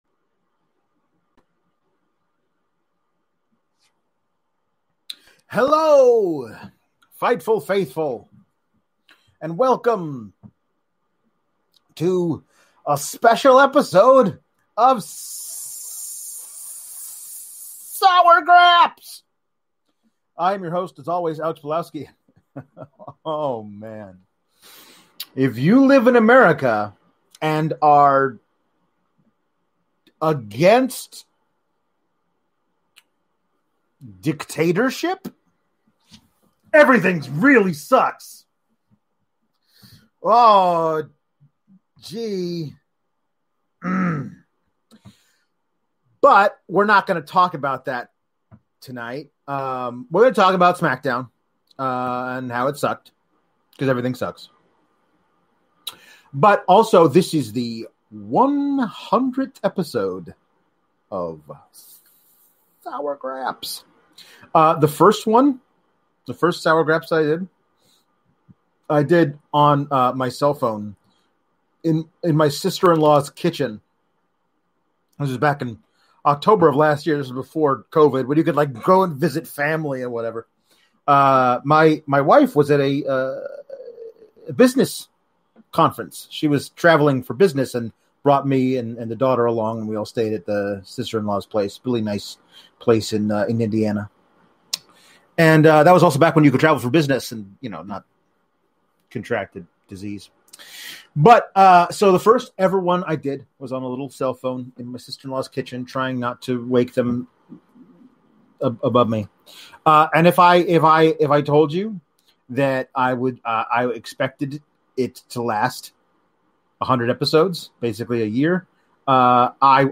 EDIT: There were some obvious technical difficulties with the end of the episode and we do apologize.